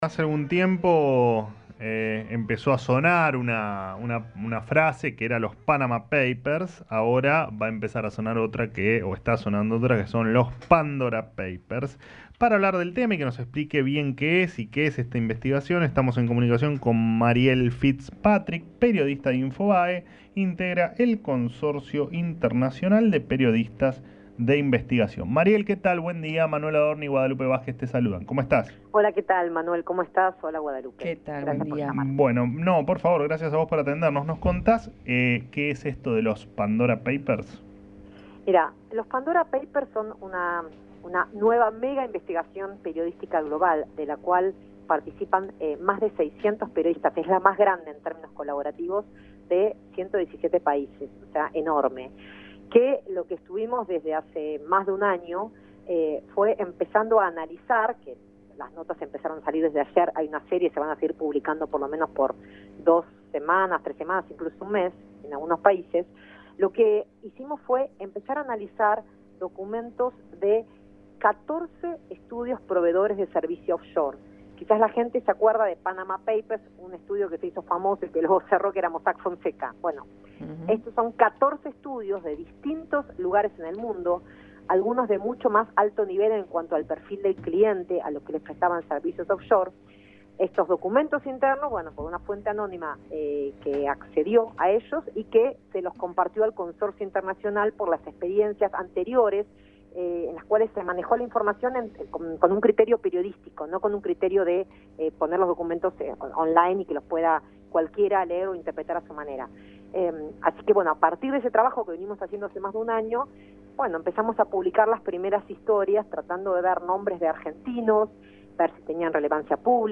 Que-son-los-Pandora-Papers-Radio-Rivadavia-AM630.mp3